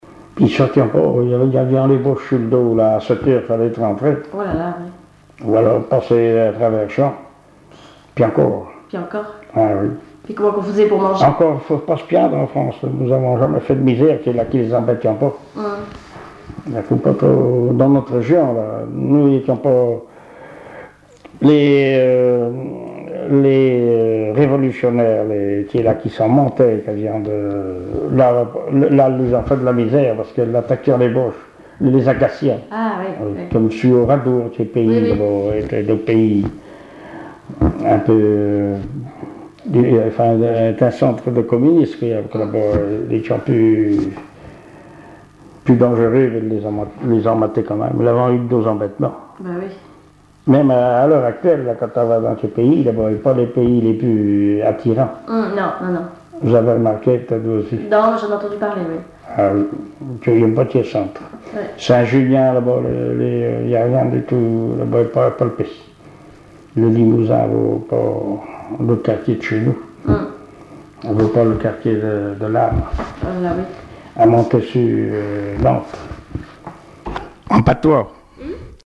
Langue Patois local
Catégorie Témoignage